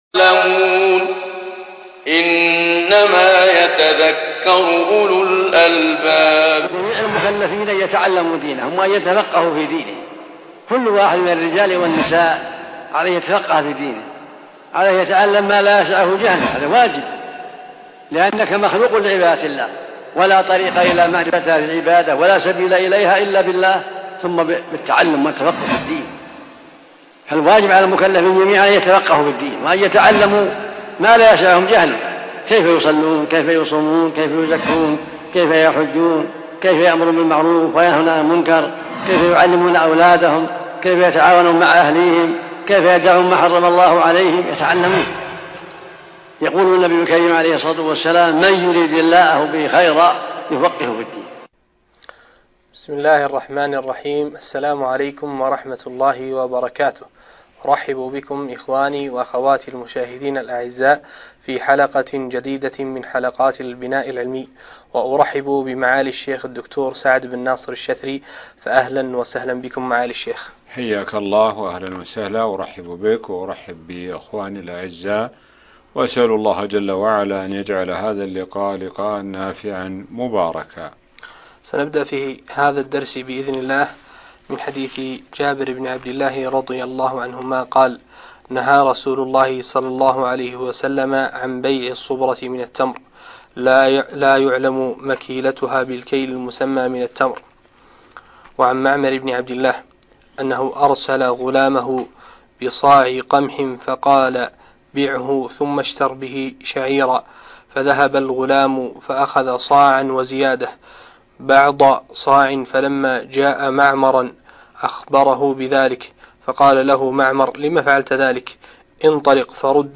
الدرس السابع عشر - المحرر في الحديث 3 - الشيخ سعد بن ناصر الشثري